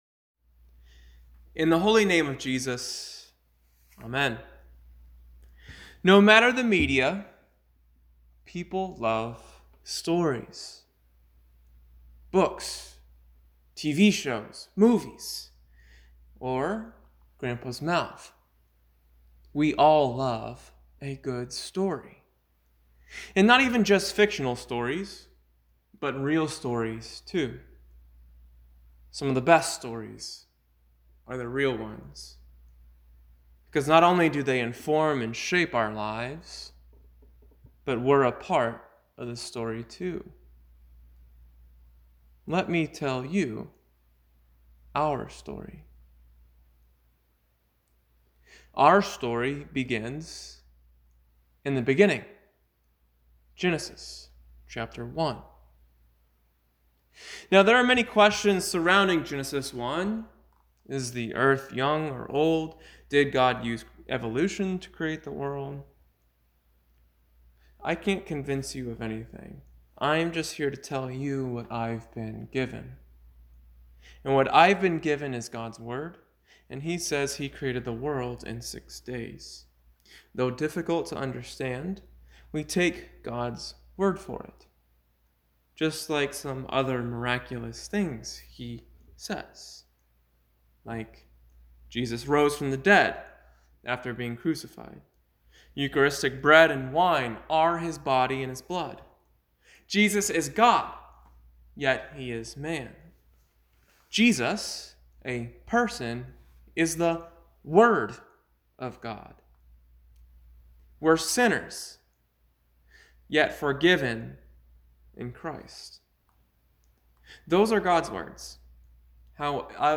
Recent Sermons
Pre-recorded Friday, November 8th, 2019